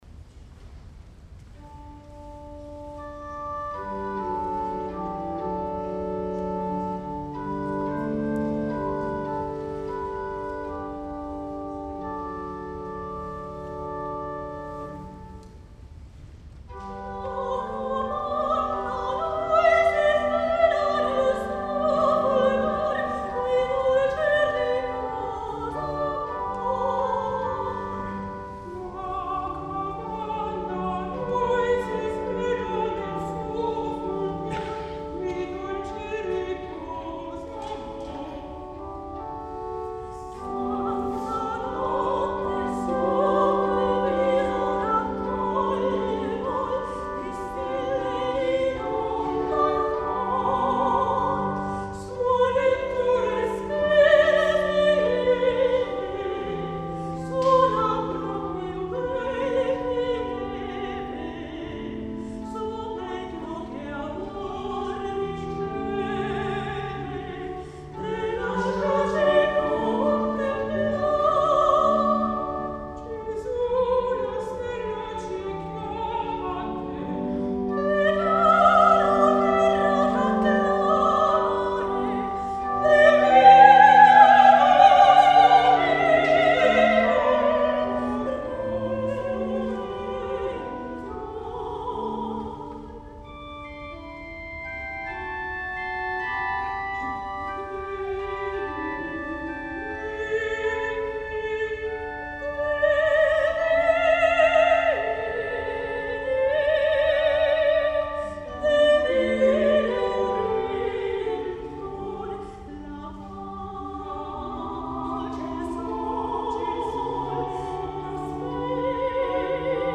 La Corale San Gaudenzio di Gambolo' (Church Choir) 2013
Registrazione audio MP3 di alcuni brani del concerto